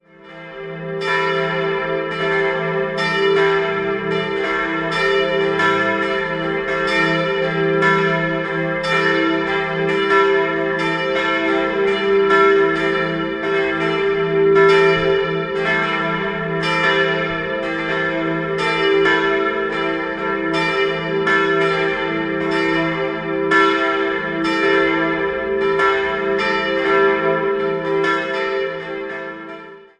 Die letzte Renovierung mit Umgestaltung des Innenraums fand im Jahr 2016 statt. 3-stimmiges Geläut: e'-g'-a' Die drei Glocken mit den Namen "Glaube", "Liebe" und "Hoffnung" wurden im Jahr 1954 von der Gießerei Bachert in Karlsruhe hergestellt.